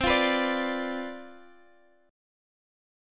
CM7sus2sus4 chord